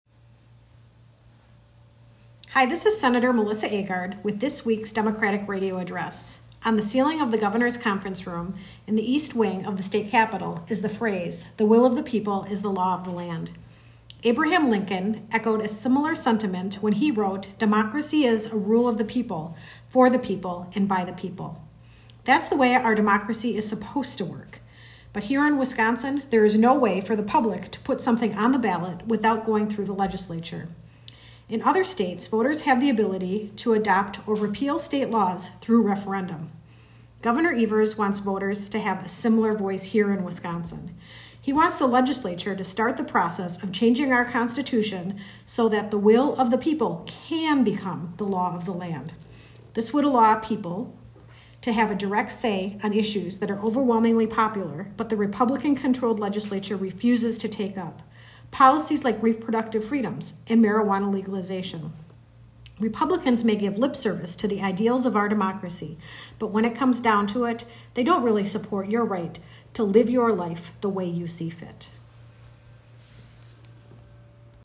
Weekly Dem radio address: Sen. Agard blasts Republicans for signaling they won't take up Gov. Evers' ballot initiative proposal - WisPolitics